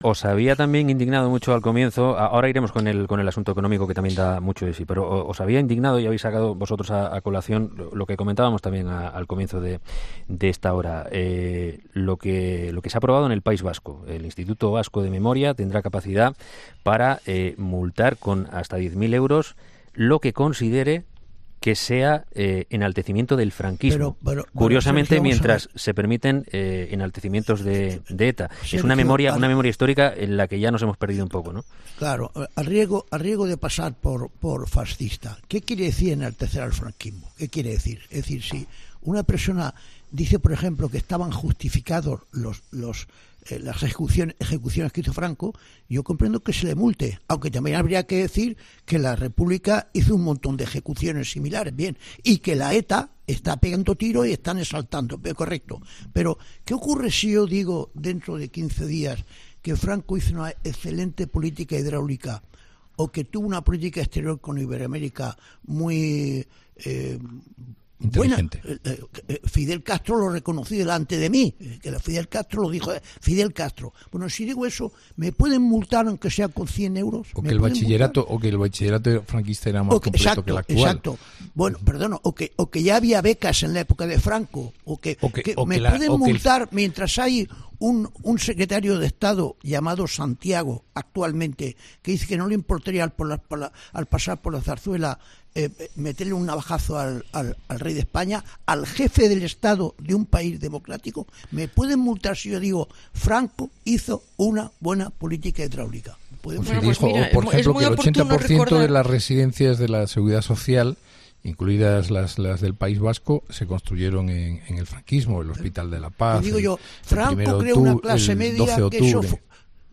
Los tertulianos han entrado a valorar la decisión que ha tomado el Gobierno del País Vasco, y especialmente Inocencio Arias, que ha sido especialmente tajante y sarcástico con su opinión sobre la nueva norma: "si una persona aprueba las ejecuciones que hizo Franco entiendo que se le multe", no sin obviar las que realizó el bando republicano.
Resultó llamativo también para todos los participantes de la tertulia, el hecho de que no se mencione nada de los crímenes de ETA y del terrorismo en nuestro país, ironizando respecto a si "se contempla o no la apología a los atentados y al terrorismo" de la banda terrorista.